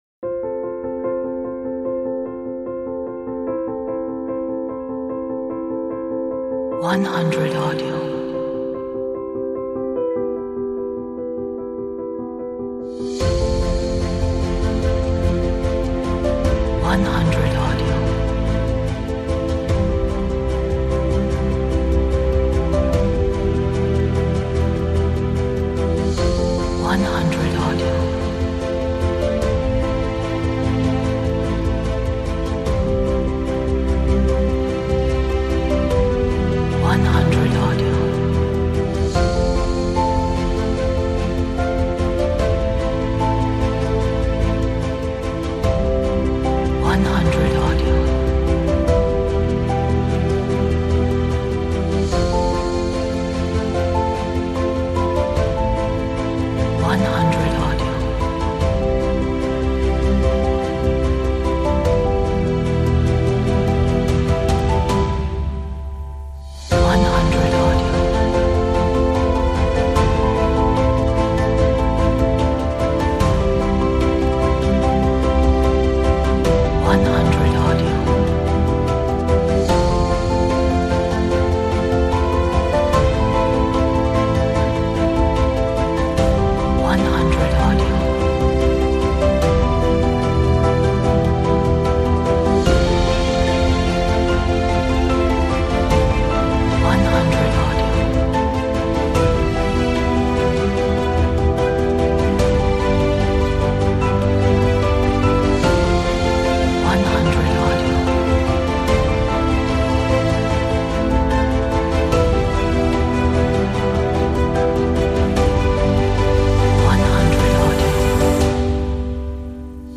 Piano Inspirational